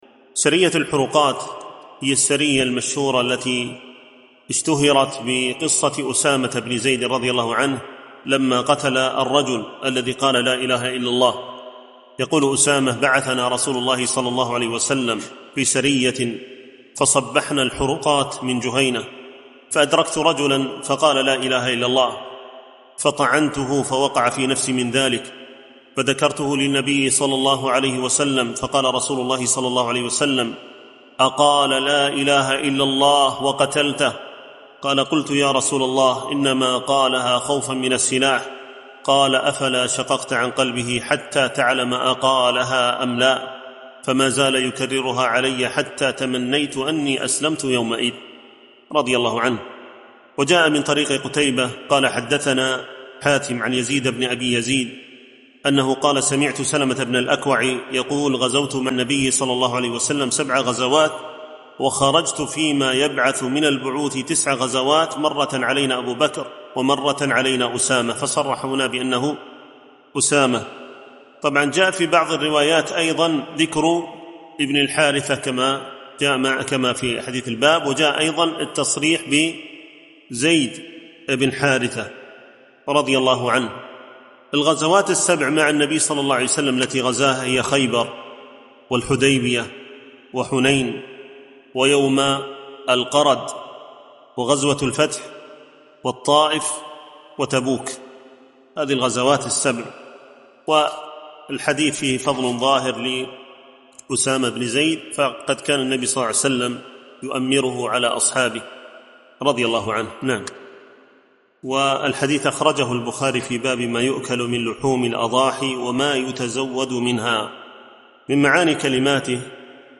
MP3 Mono 48kHz 192Kbps (CBR)